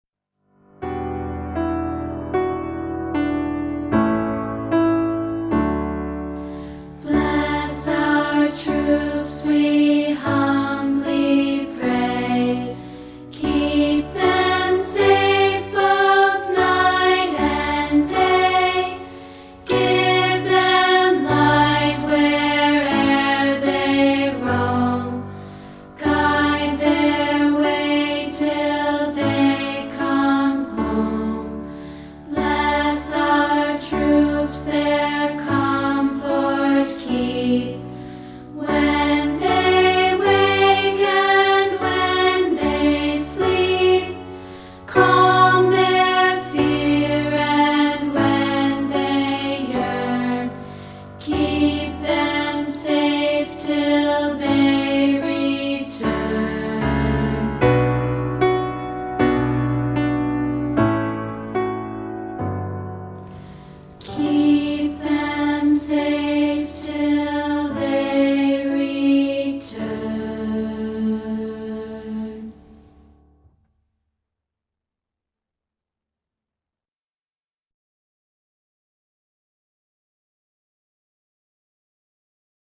hymn-like song
including part 2 isolated in MP3 format.